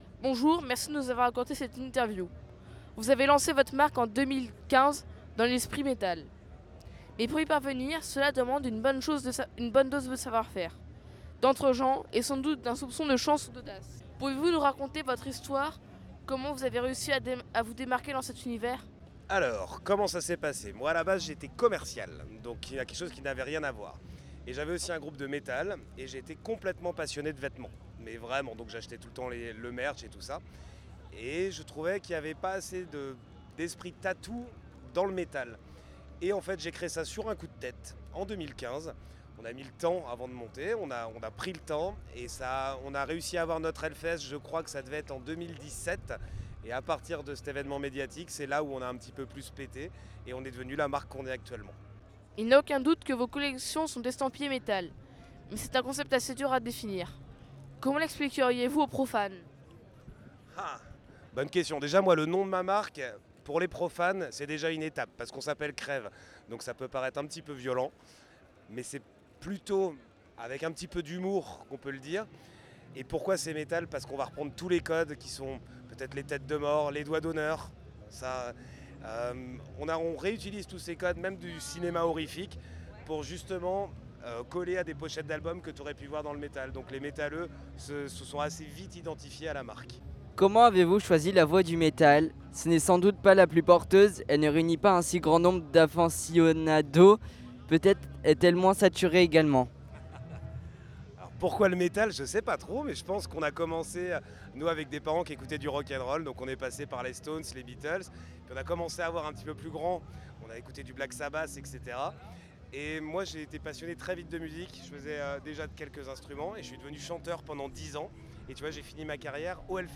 Voici l’interview :
HELLFEST 2025 - rencontres autour de l’écosystème du Metal